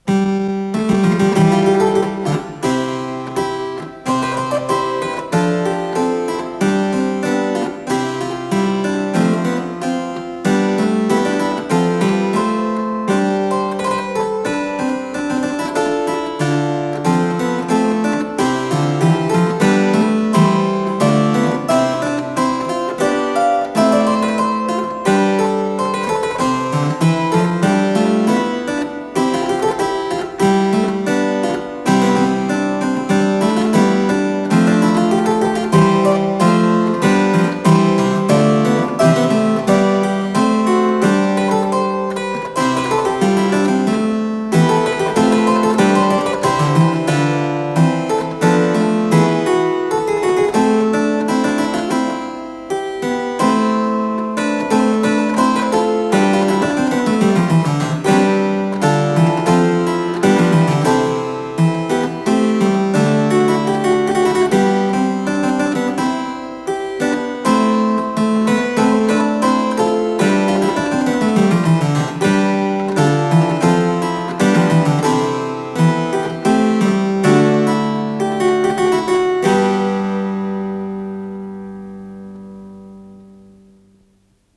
КомпозиторыАноним (Польша, 16 век)
ВариантДубль моно